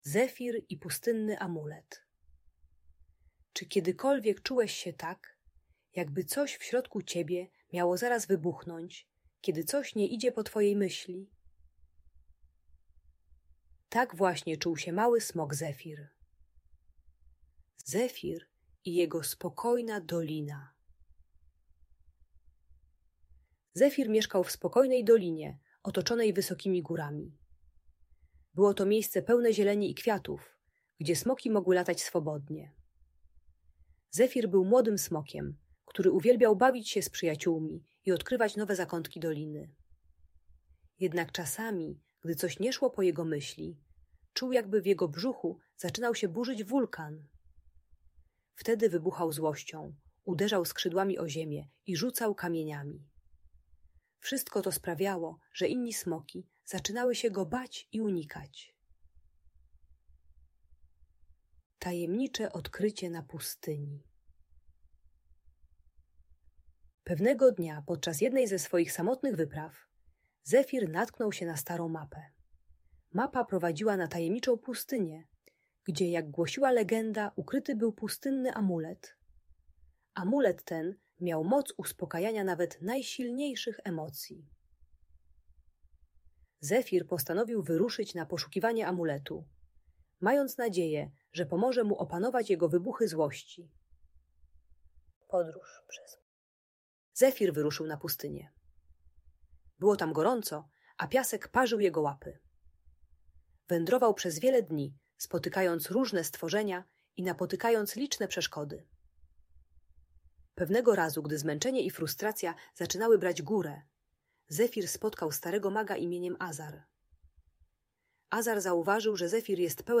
Zefir i Pustynny Amulet - Audiobajka